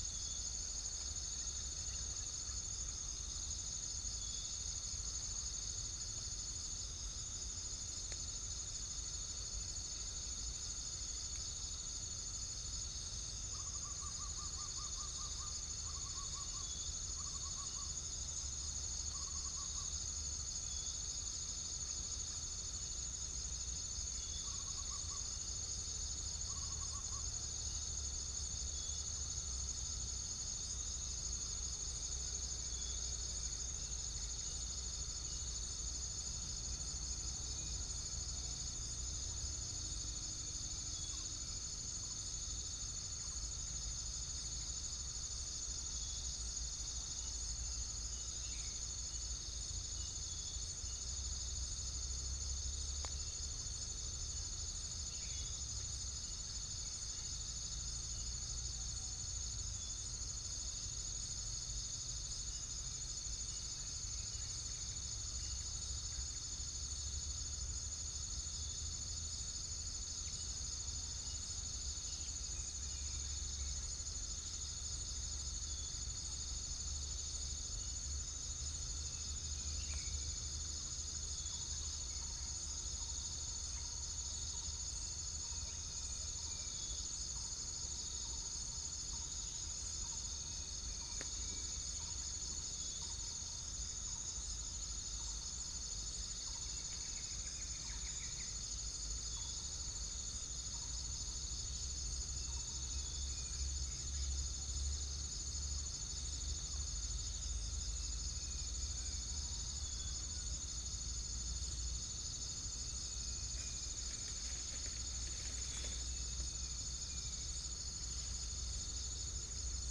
Chalcophaps indica
Geopelia striata
biophony
Centropus sinensis
Psilopogon oorti
Pycnonotus goiavier